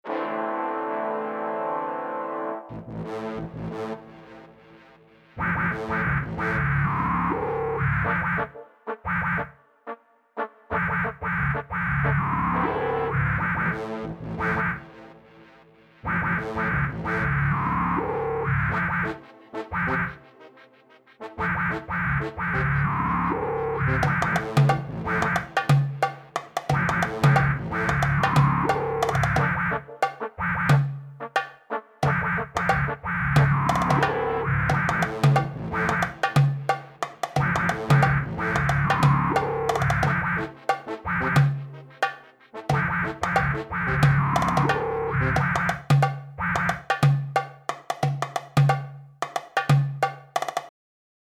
2005 Электронная Комментарии